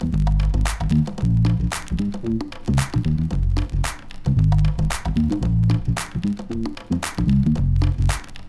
feel bass funky esmeralda - A#m - 113.wav
Bass
feel_bass_-_A_sharp_m_-_113_WJh.wav